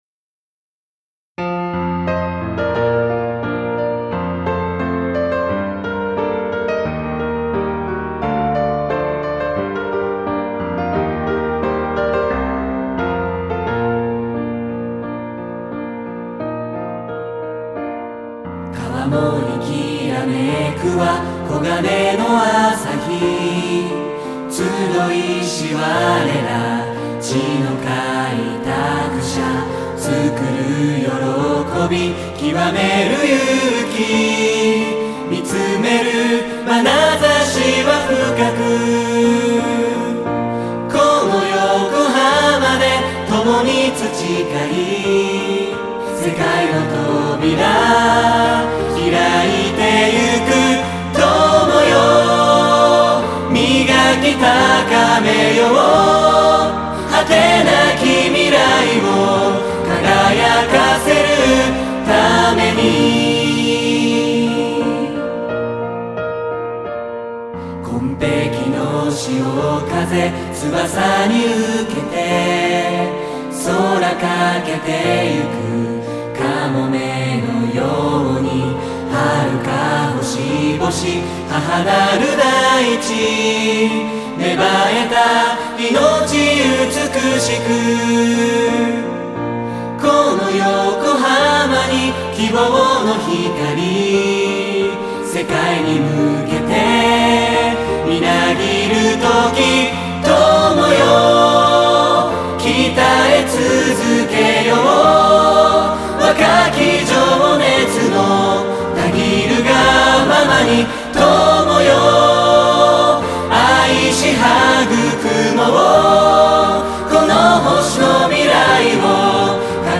我らが校歌である。
曲としての特徴 全体的にスローテンポで校歌らしからぬポップな曲調である。